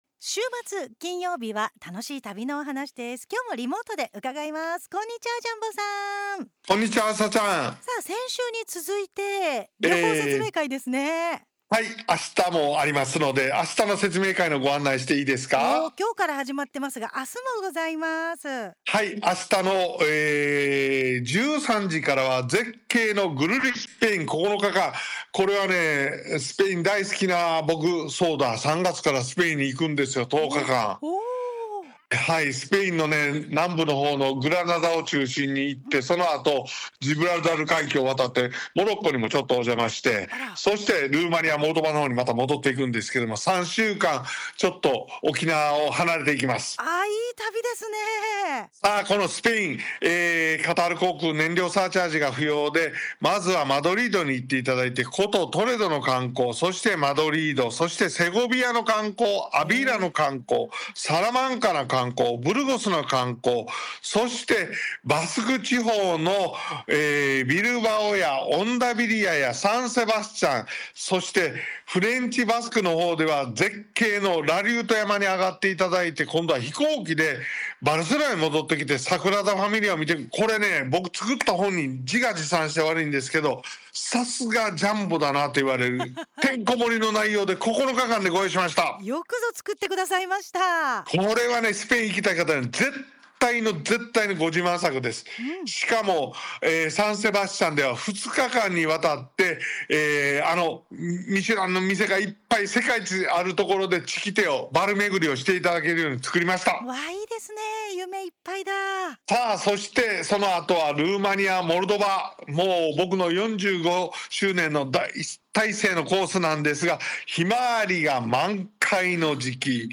★『（2/27・2/28）海外旅行説明会』2026年2月27日(金)ラジオ放送